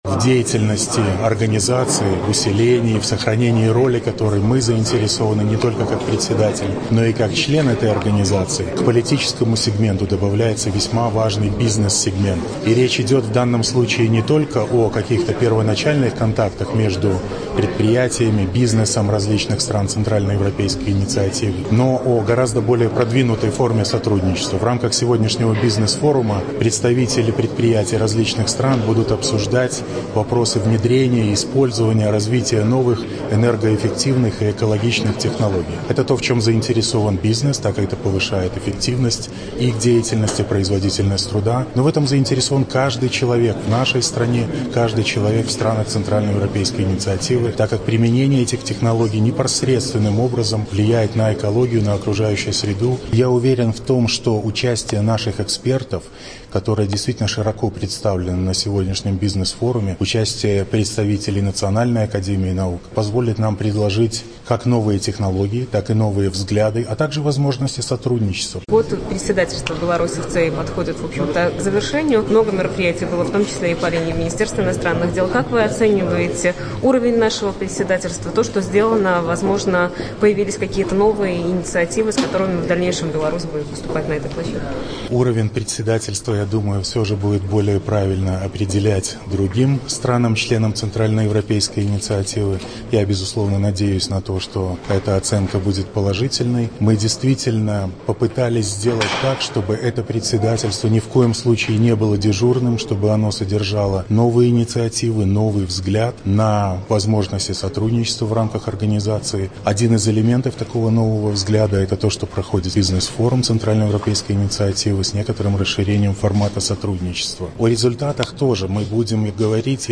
Это заместитель министра иностранных дел Беларуси Олег Кравченко отметил в интервью Радио «Беларусь» во время бизнес-форума ЦЕИ «Энергоэффективные технологии».
Заместитель министра иностранных дел Беларуси Олег Кравченко